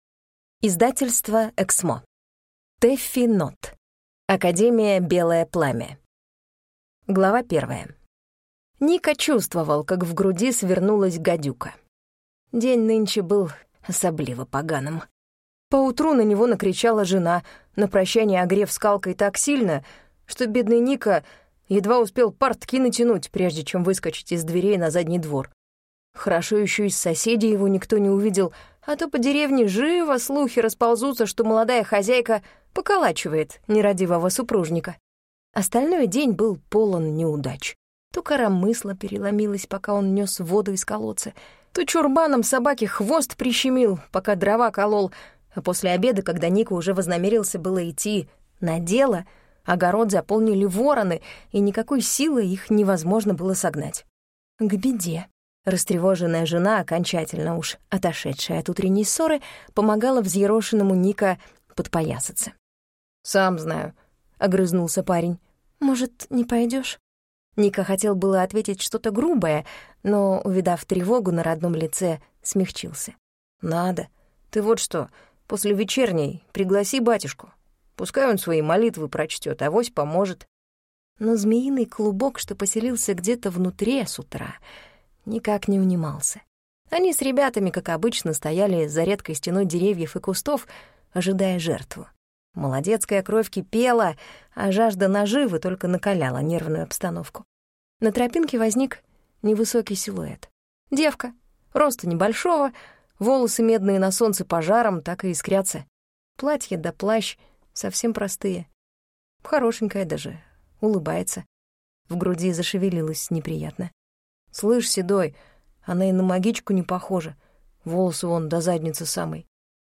Аудиокнига Академия «Белое пламя» | Библиотека аудиокниг